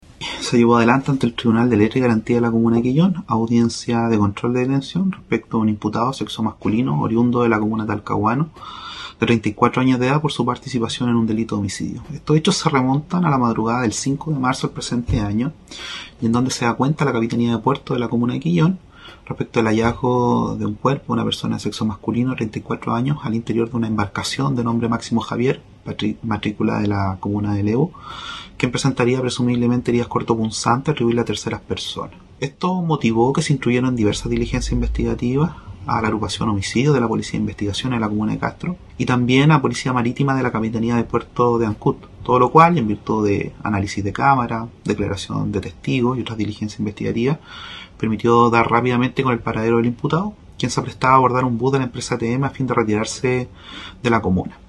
Recordemos que personal de la Capitanía de Puerto corroboró que en una embarcación con matrícula de Lebu, se encontraba el cuerpo sin vida del tripulante con signos evidentes de la intervención de terceras personas, informó el fiscal de Quellón Fabián Fernández
07-FISCAL-QUELLON-AMPLIAN-DETENCION.mp3